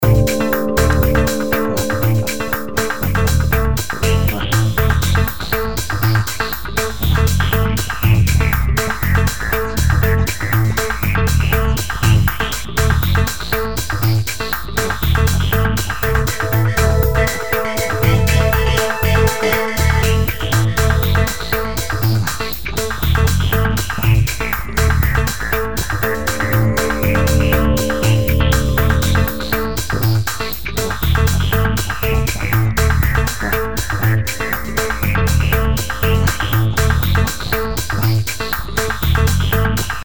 Jingles to listen to:
synthétiseur, chant, guitare, percussion, drums